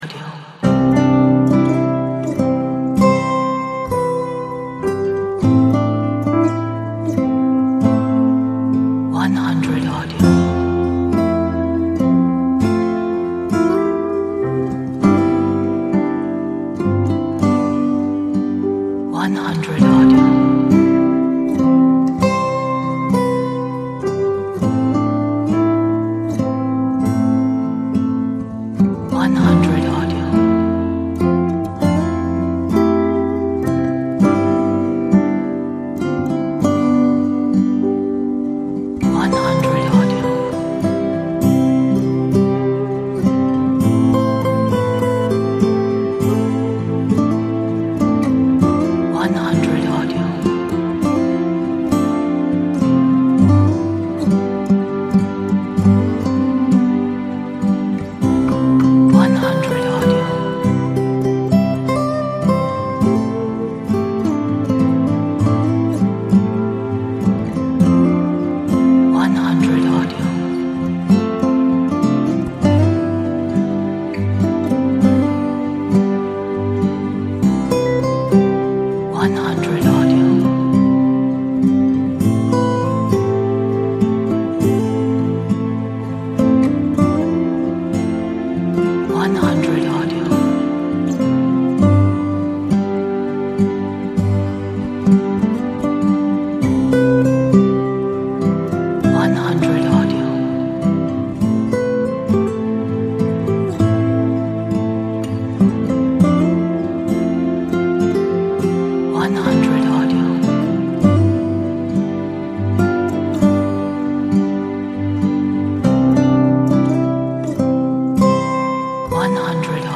这是一首美丽浪漫的音乐。配有钢琴，原声吉他和弦乐。